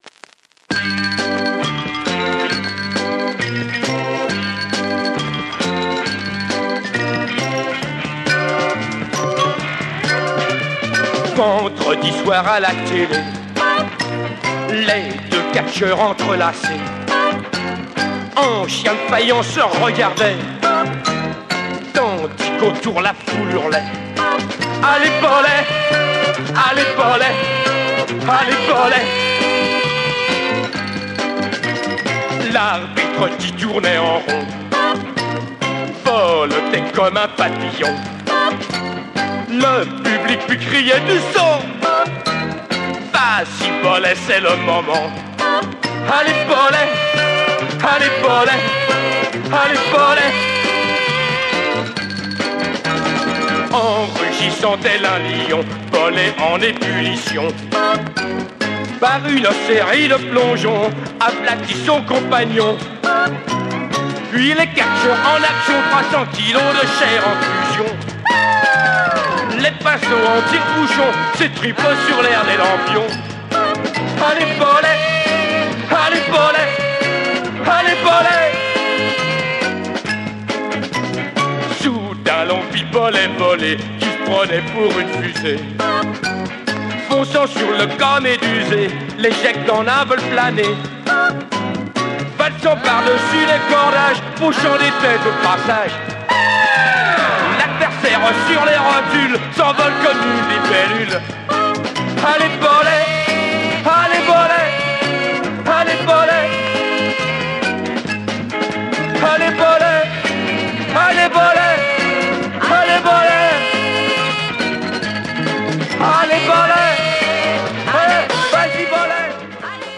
French Row Beat Soul groove single